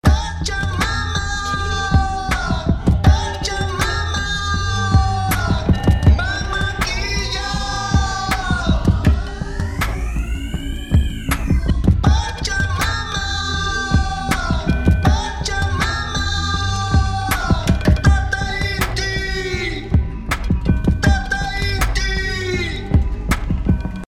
Dadurch verschiebt sich die Relation zwischen Raum- und Frontmikrofon zugunsten des Direktsignals.
Nun möchte ich die Stimme doppelt oder breiter machen.
Der Effekt projiziert temposynchrone Echos mit einer Dauer in Notenwerten und einer Transponierung um eine Oktave nach unten im Panorama.
Zusätzlich verwende ich wieder GRM Shuffling mit leichter Tonhöhenmodulation und mit ebenfalls in etwa temposynchronen Echos (die sich hier leider nicht in Form von Notenwerten eingeben lassen; man muss sie also errechnen.